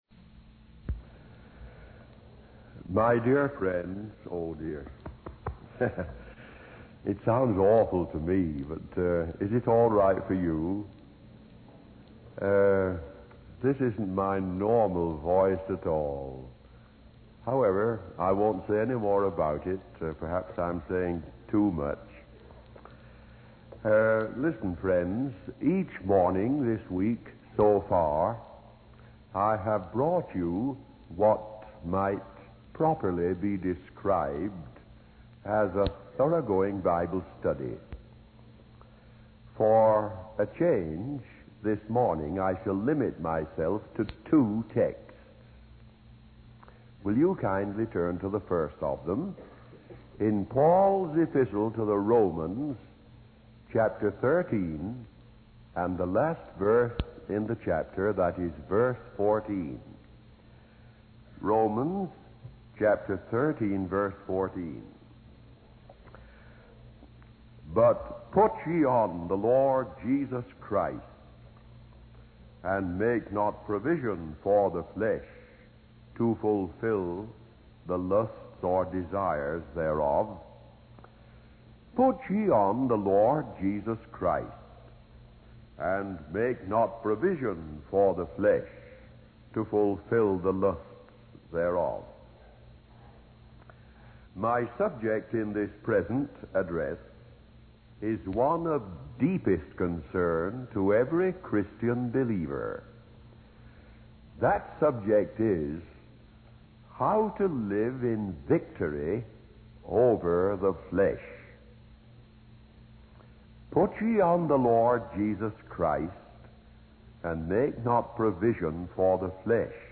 The sermon concludes with practical steps for maintaining this victorious life in Christ.